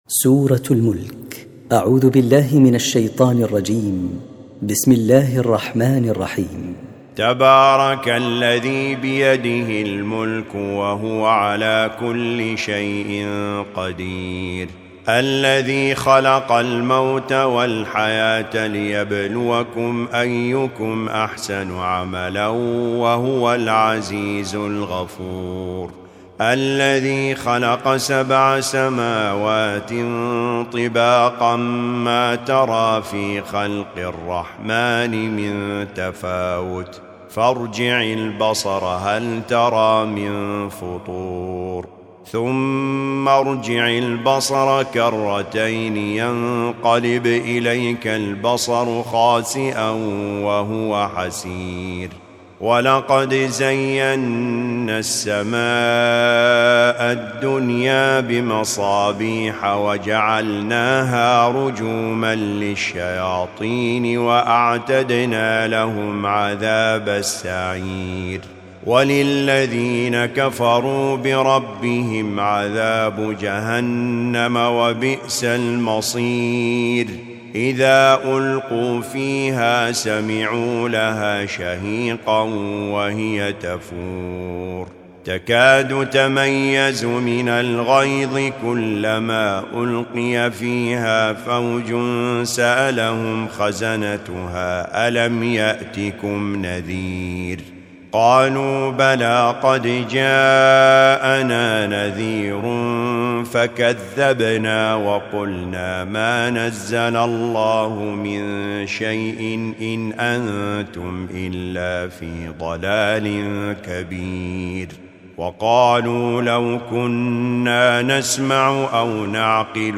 الجزء التاسع و العشرون : جزء تبارك كامل > المصحف المرتل